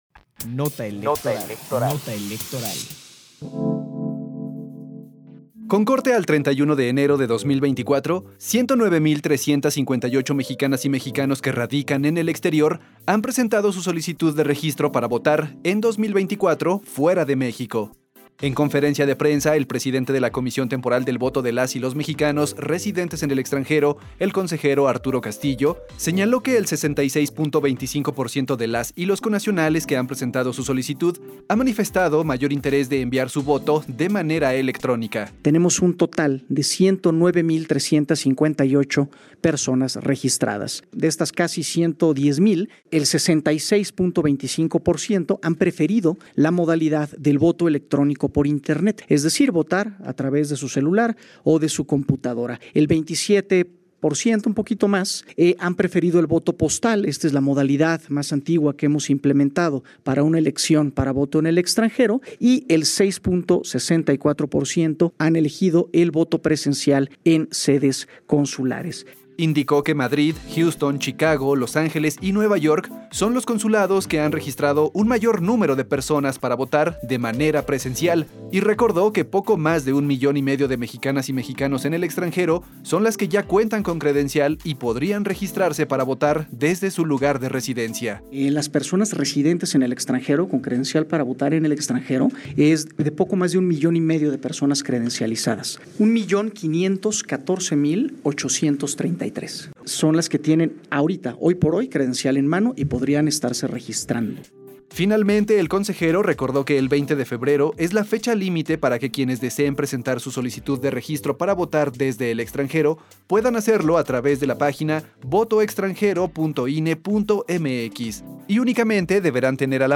Nota de audio sobre el voto desde el extranjero, 31 de enero de 2024